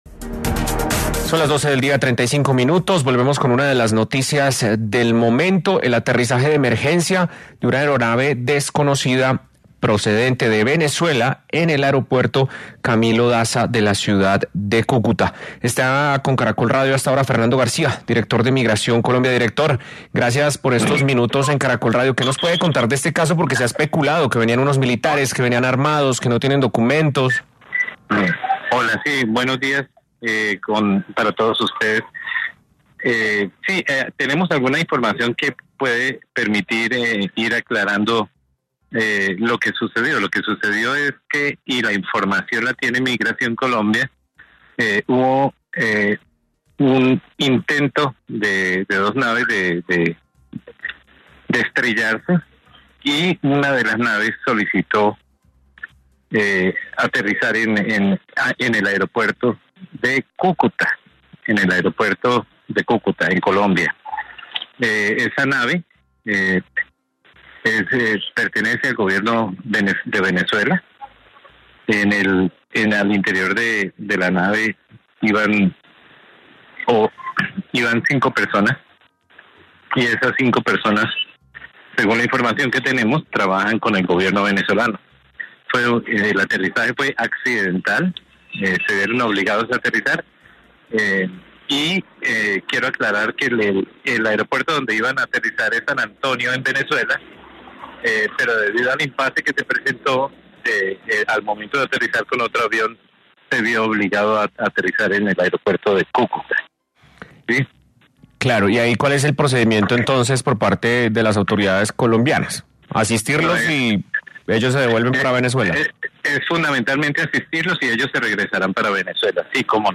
En diálogo con Caracol Radio el director de Migración Colombia, Fernando García, aseguró que el aterrizaje de un avión venezolano que despegó desde la ciudad de Caracas, y donde transportaba funcionarios militares venezolanos, fue una emergencia.